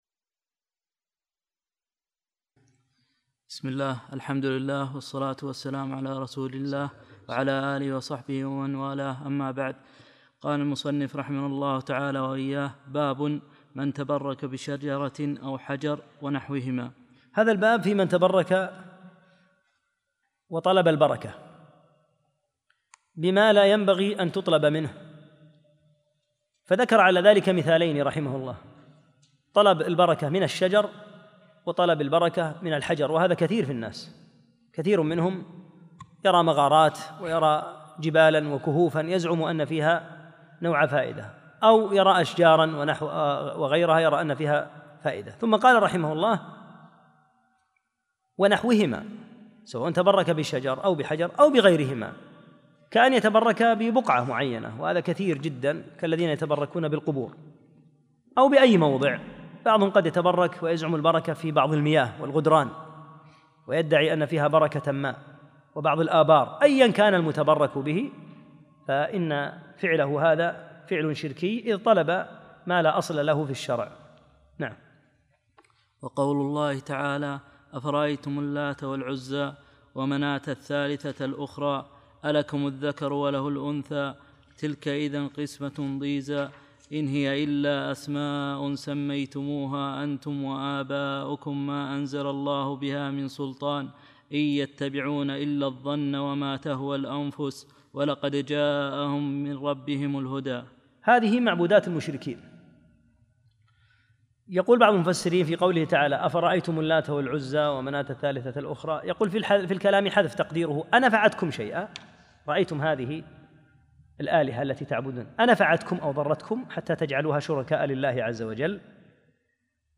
الدرس التاسع